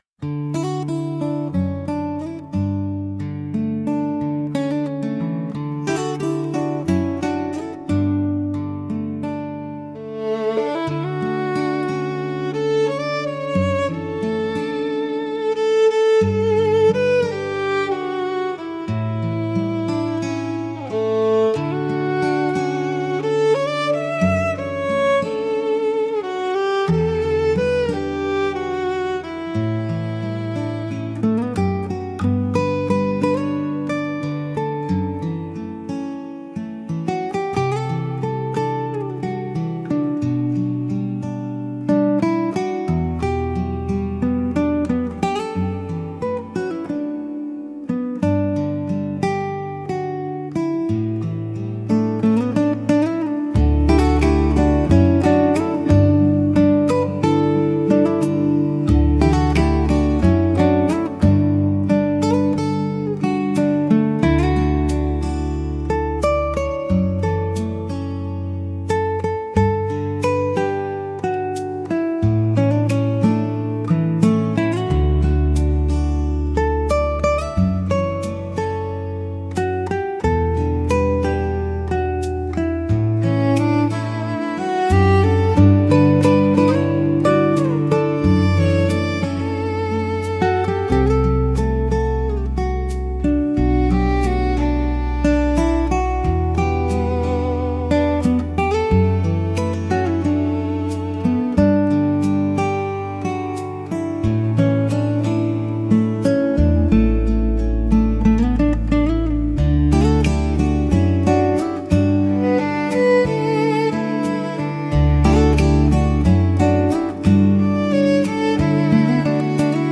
聴く人に安心感と癒しを与え、静かな集中や心の安らぎをサポートしながら、邪魔にならない背景音楽として活用されます。